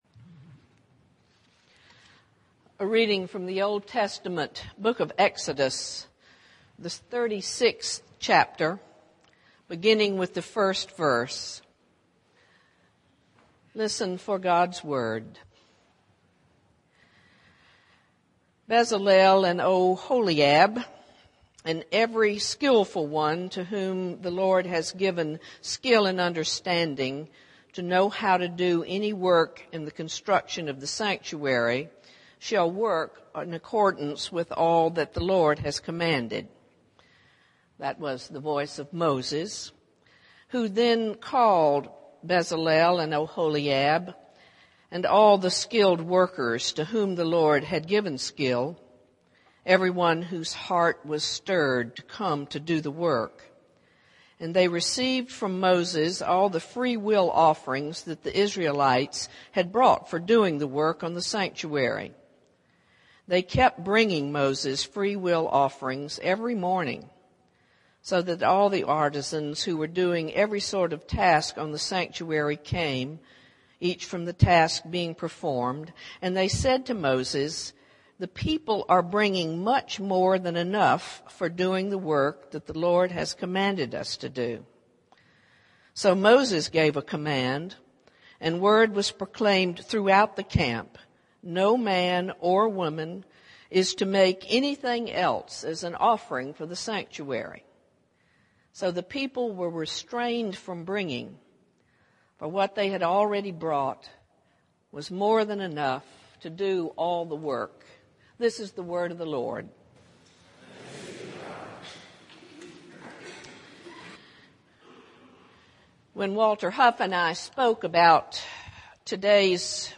worship_nov18_2__sermon.mp3